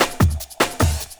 50LOOP01SD-L.wav